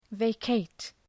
Προφορά
{veı’keıt}